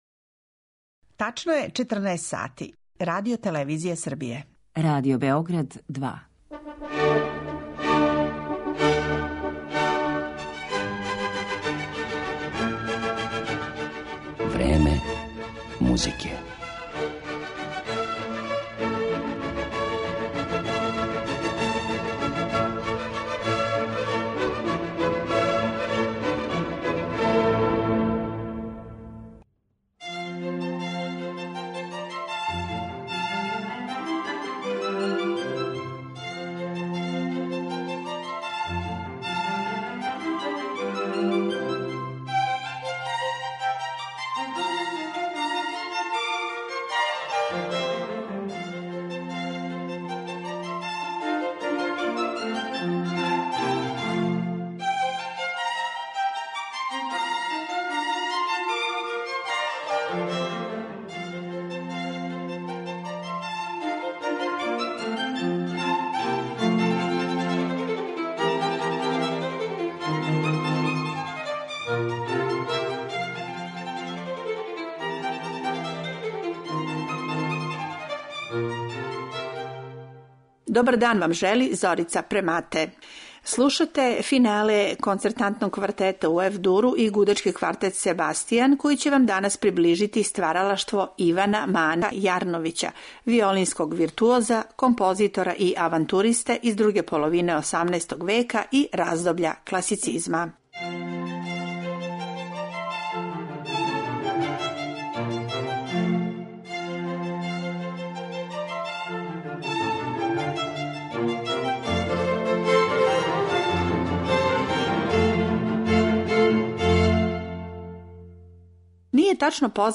Његова дела свира гудачки квартет „Себастијан"